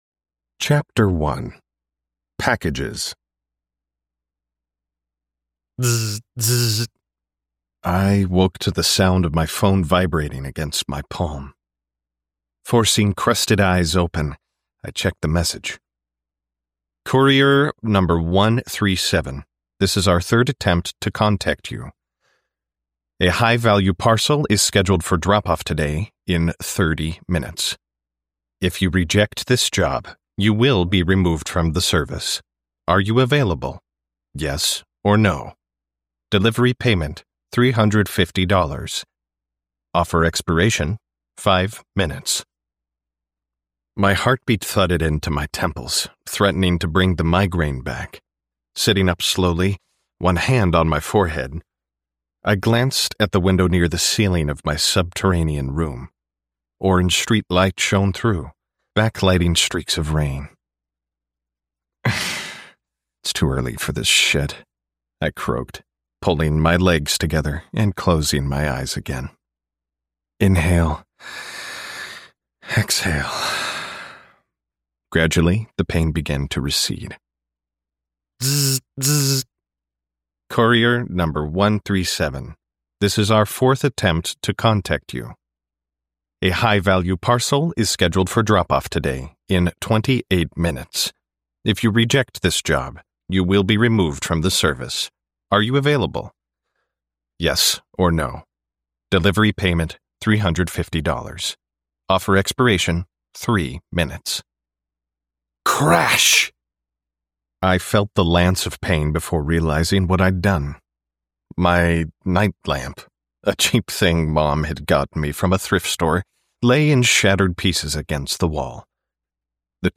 Apocalypse BREAKER Audiobook Sample